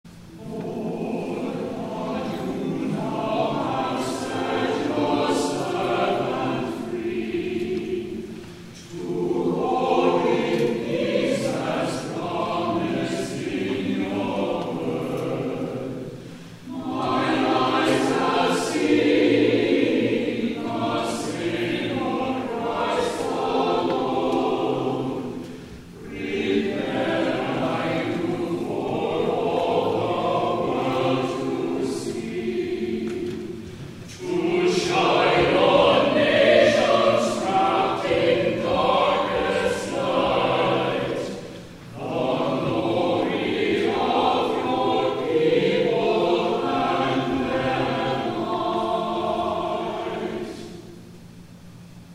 *THE CHORAL RESPONSE